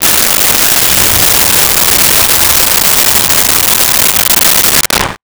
Car Start 01
Car Start 01.wav